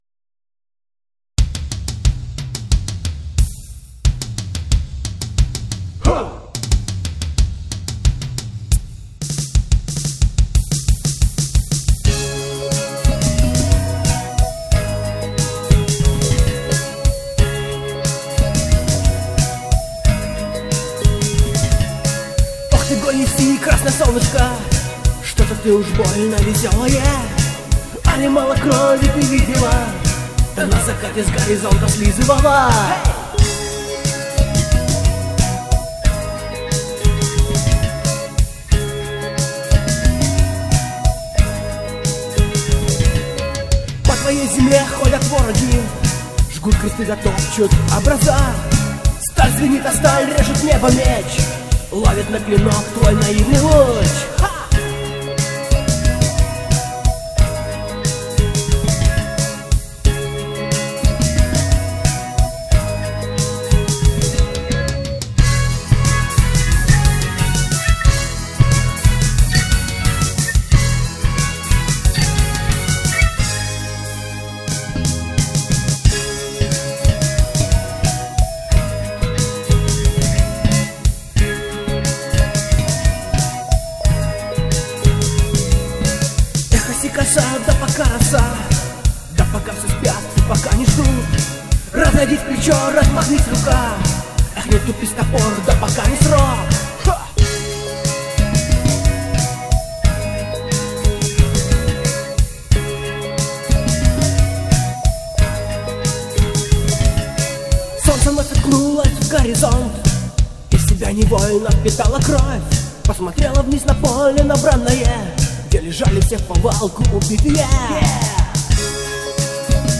Клавиши, гитара, перкуссия, вокал